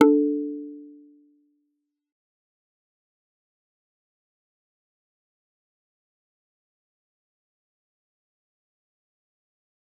G_Kalimba-D4-f.wav